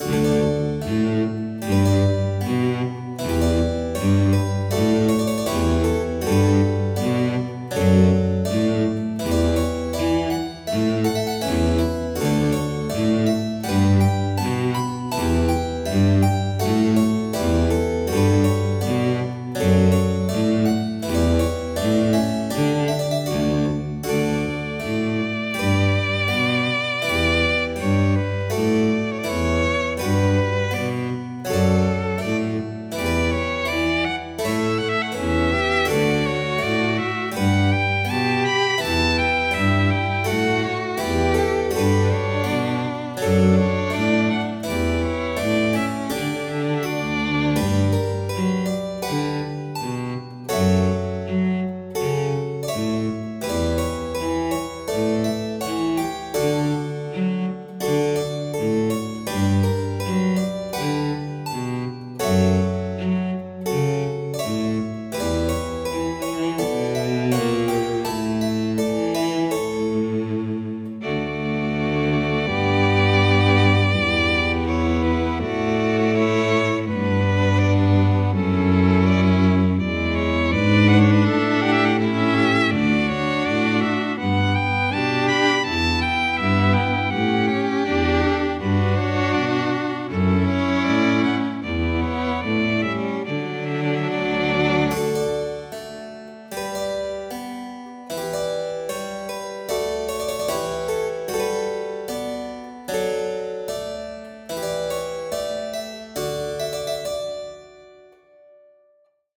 -日常系